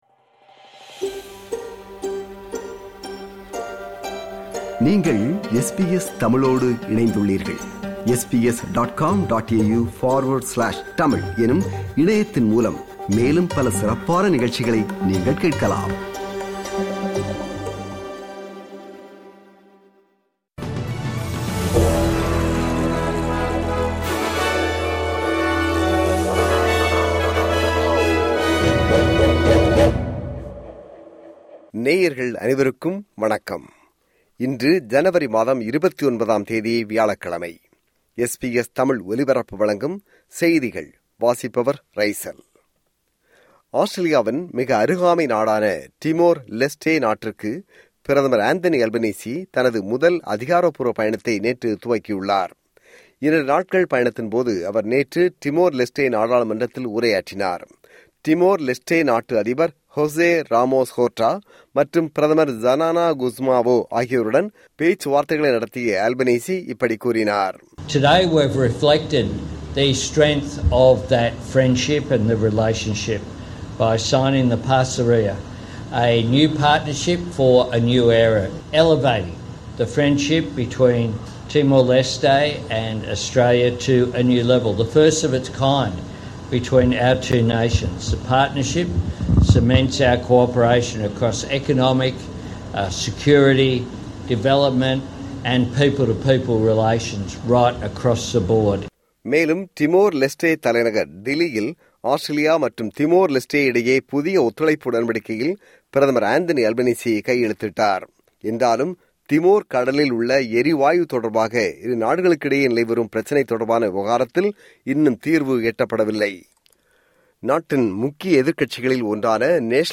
SBS தமிழ் ஒலிபரப்பின் இன்றைய (வியாழக்கிழமை 29/01/2026) செய்திகள்.